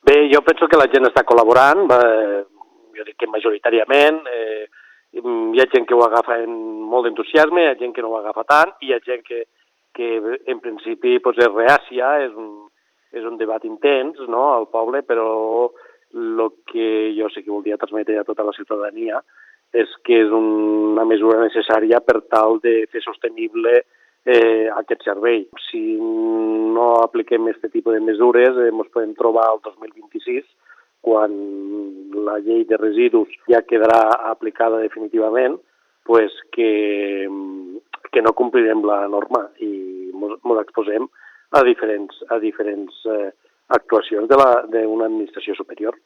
Damià Grau, ha volgut transmetres a la ciutadania que es tracta d’una mesura necessària per fer sostenible el servei de recollida d’escombraries i evitar possibles sacions d’administracions superiors quan s’apliqui definitivament la llei de residus el 2026…